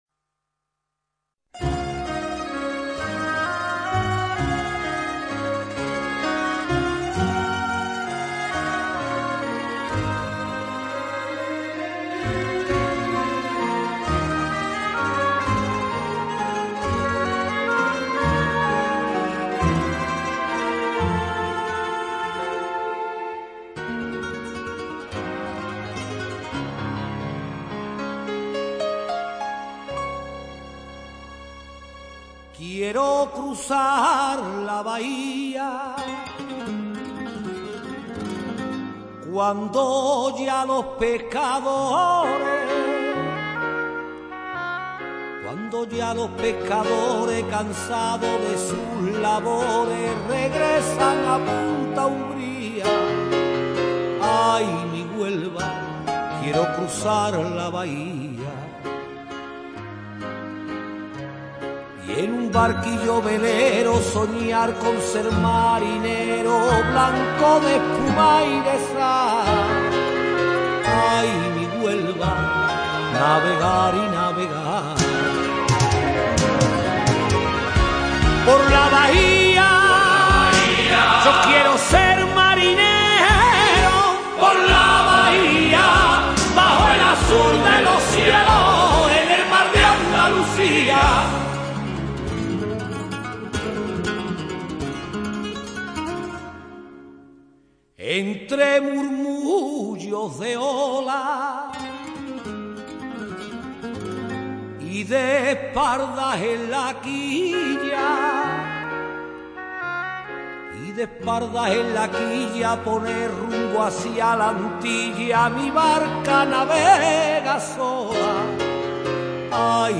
Guitarra
Temática: Marinera